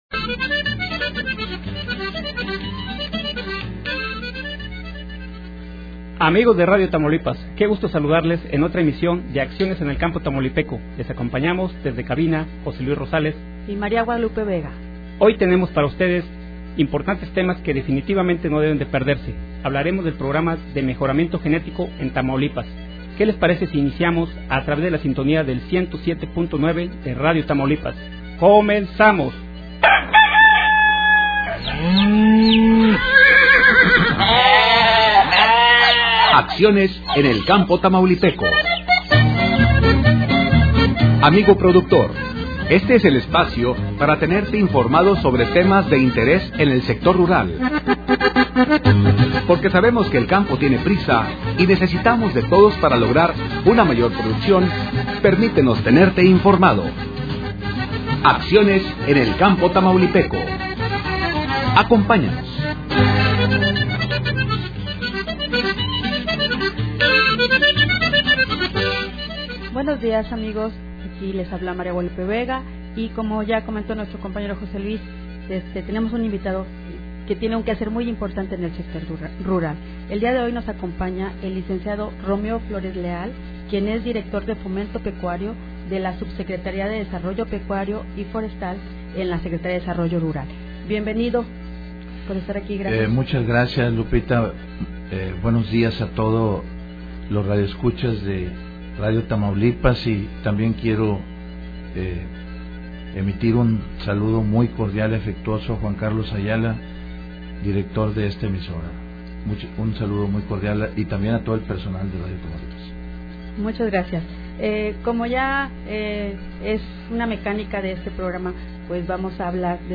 El Lic. Romeo Flores Leal, Director de Fomento Pecuario de la Subsecretaría de Desarrollo Pecuario y Forestal de la Secretaría de Desarrollo Rural, inició la entrevista comentando la estructura de la dirección a su cargo y los programas y proyectos que operan tanto estatales como en concurrencia con la Federación. Continuó, resaltando el Programa de Mejoramiento Genético, en que consiste, su mecánica operativa, así como los conceptos en los montos de apoyo y los requisitos.